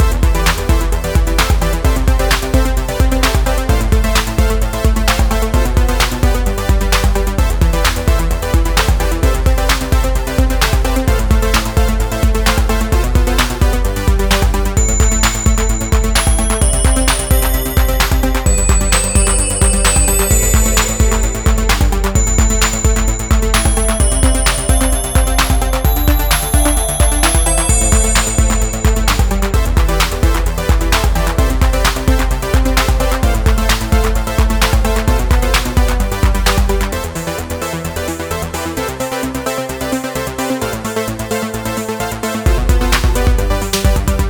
Renoise